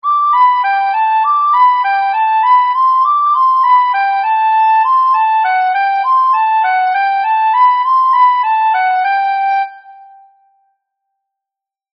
obabočská lidová píseň Zvedla paní – Sáhla paní – Nebe padá – Když Jaroslav cz en Nebe padá Nebe padá, nebe padá, / není o nic opřeno; Heraklovi sklouzla pata, / a potom i koleno.